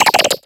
Cri de Flingouste